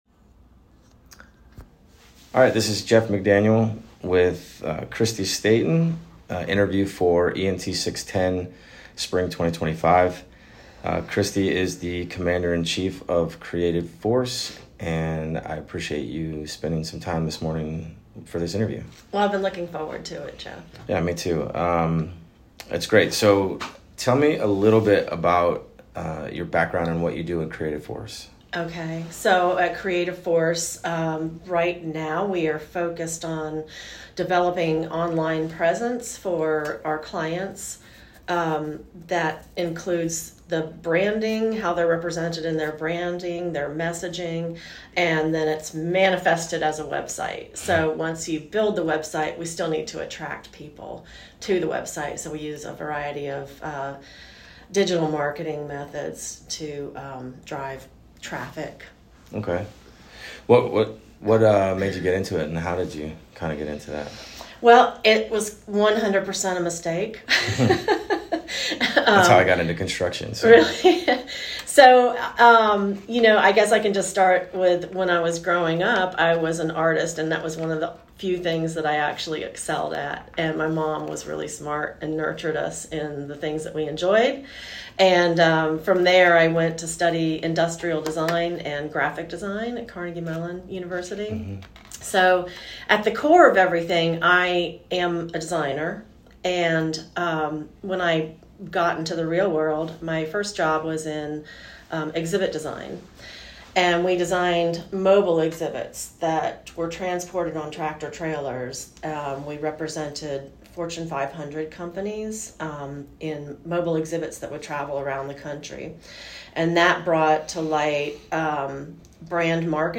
Entrepreneur Audio Interviews